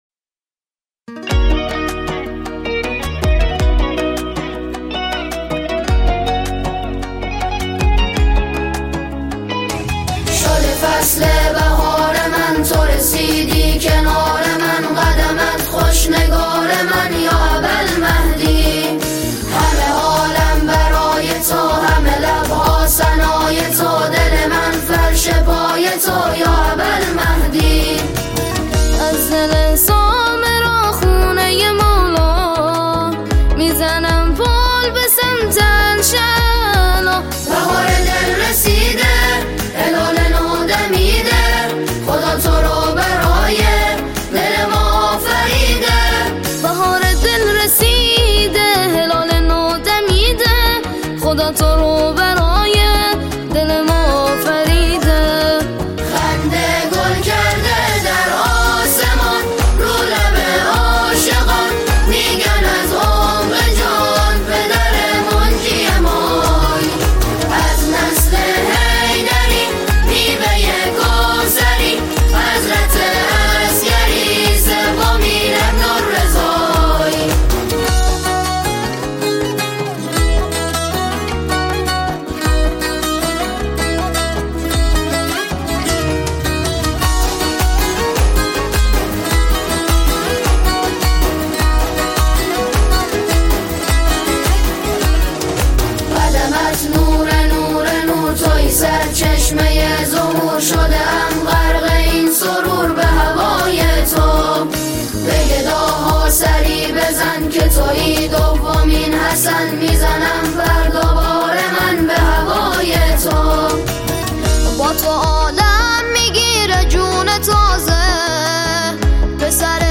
را از طریق اجرای جمعی و موزیک حماسی منتقل می‌کند
ژانر: سرود